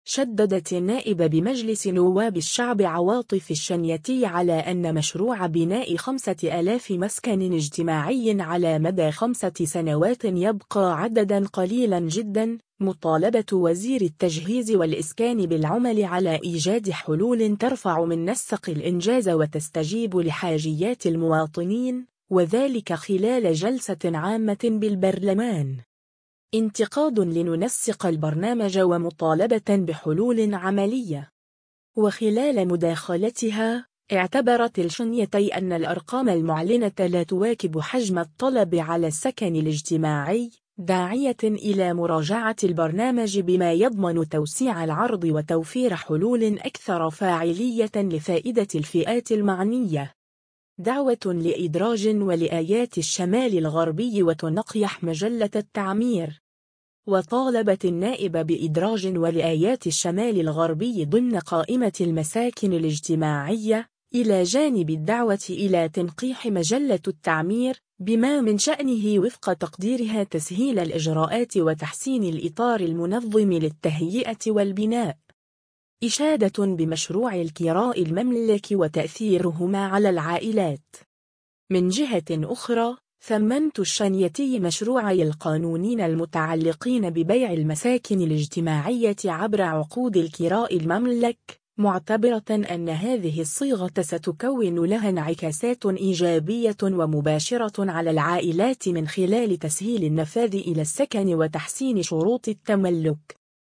شدّدت النائب بمجلس نواب الشعب عواطف الشنيتي على أن مشروع بناء 5 آلاف مسكن اجتماعي على مدى 5 سنوات يبقى “عددا قليلا جدا”، مطالبة وزير التجهيز والإسكان بالعمل على إيجاد حلول ترفع من نسق الإنجاز وتستجيب لحاجيات المواطنين، وذلك خلال جلسة عامة بالبرلمان.